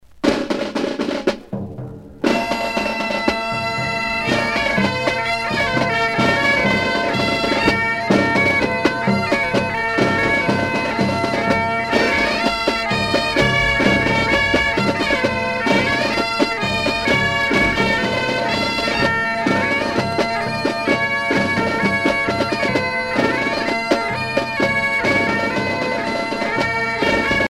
danse : jabadao
Pièce musicale éditée